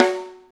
high rim p.wav